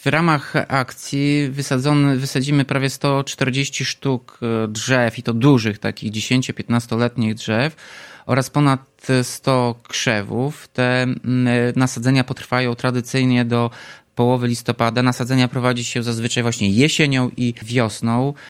– Wśród sadzonek znalazły się 15 letnie dęby czerwone, lipy srebrzyste, graby oraz platany – mówi Tomasz Andrukiewicz prezydent Ełku: